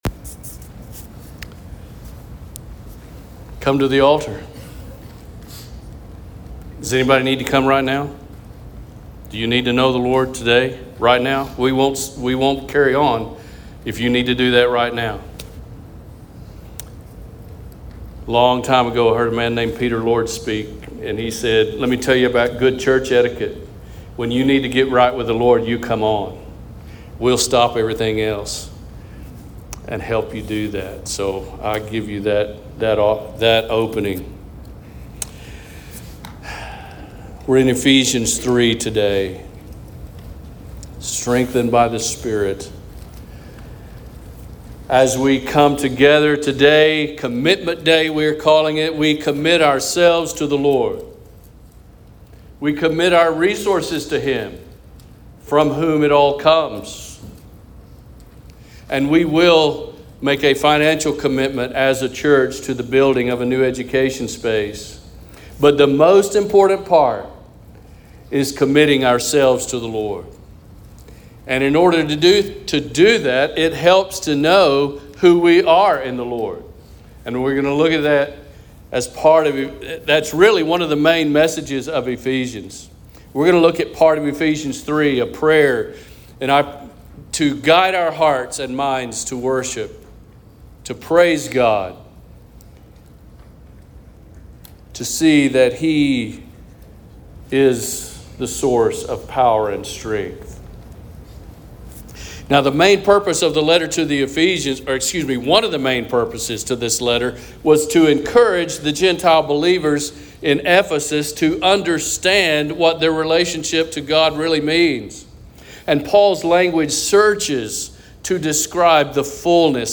Sermons | Lawn Baptist Church